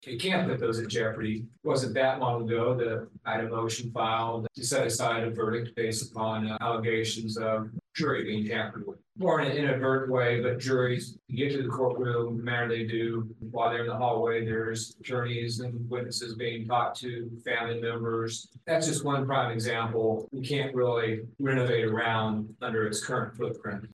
21st Judicial District Chief Judge Grant Bannister spoke Thursday to the commission, encouraging they consider facility upgrades to the courthouse.